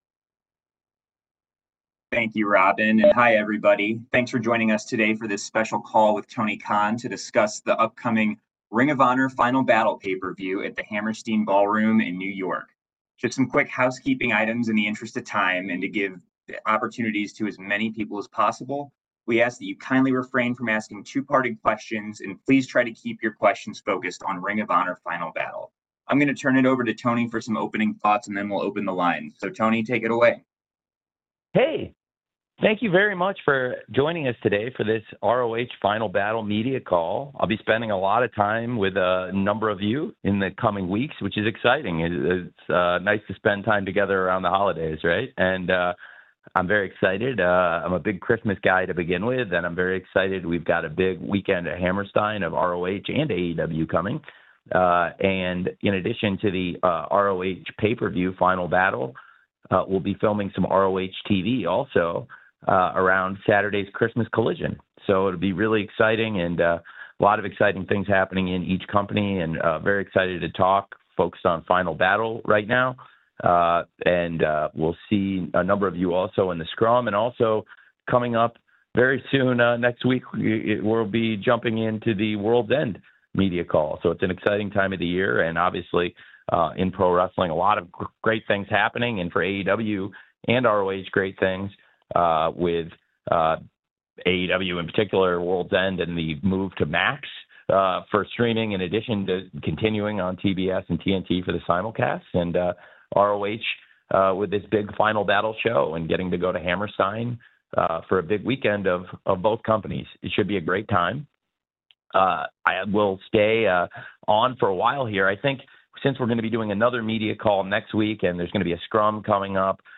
Media call with Tony Khan about Ring of Honor's Final Battle 2024.